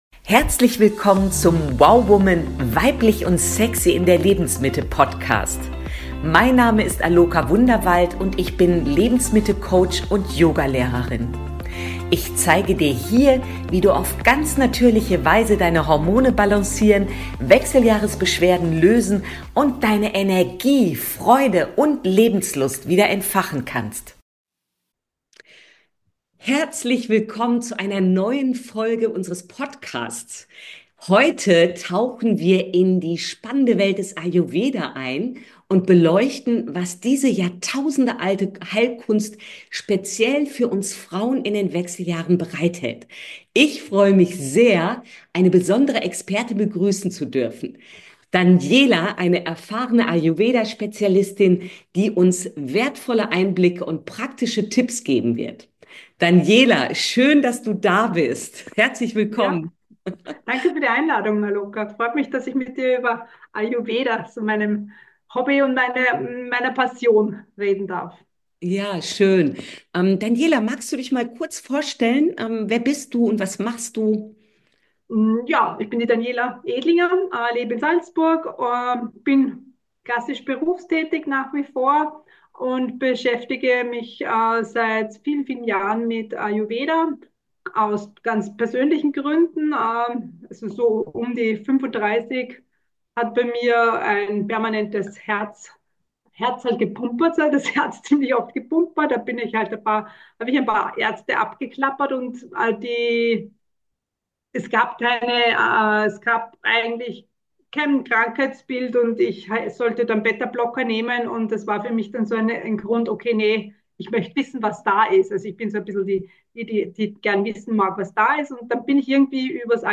Episode Nr. 93: Interview